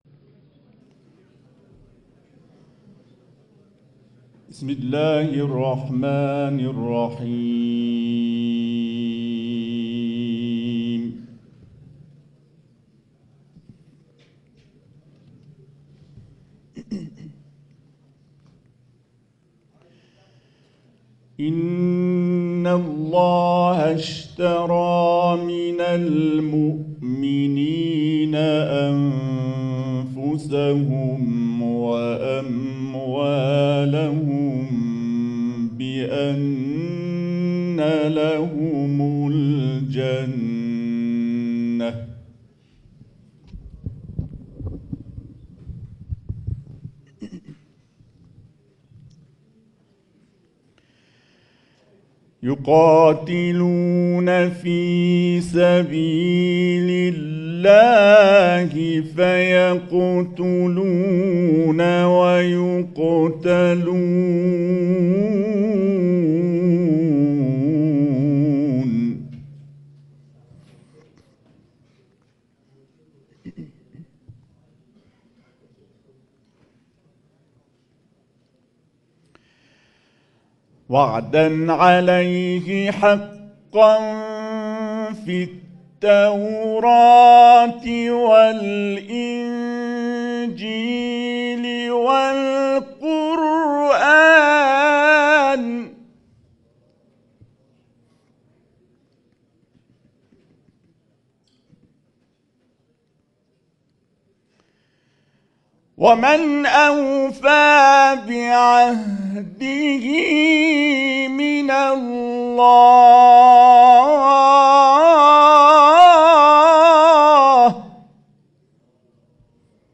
صوت تلاوت آیاتی از سوره‌های «توبه»، «عصر»، «کافرون»
برچسب ها: تلاوت قرآن ، قاری بین المللی ، اردبیل